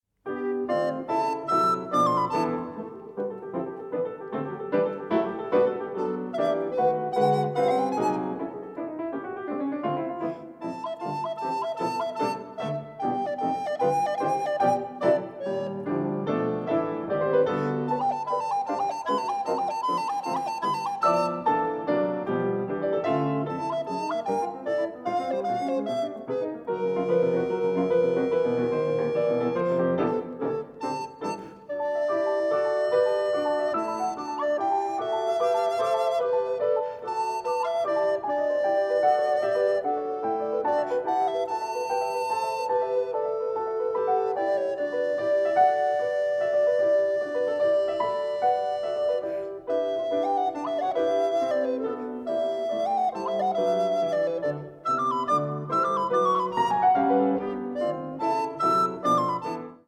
recorder
piano